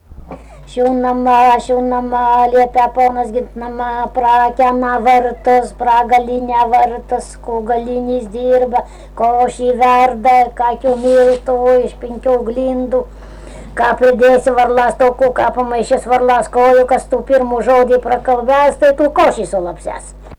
smulkieji žanrai
Rageliai
vokalinis